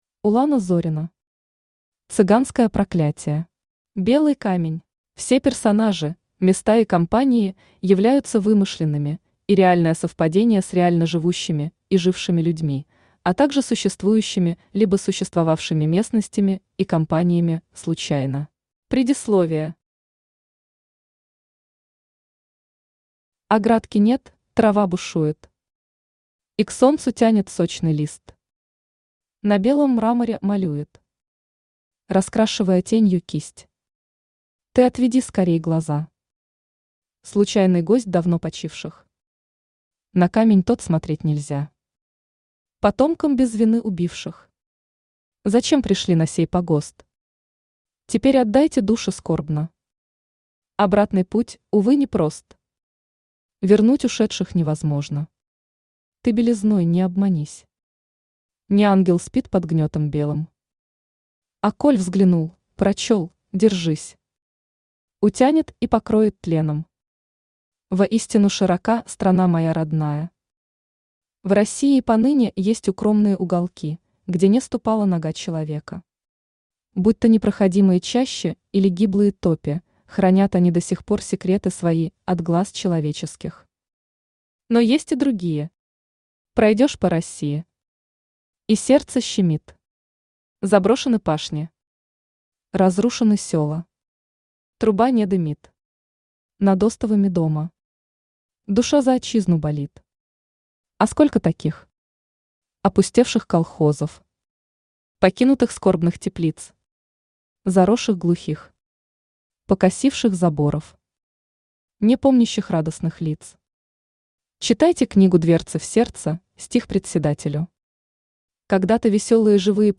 Белый камень Автор Улана Зорина Читает аудиокнигу Авточтец ЛитРес.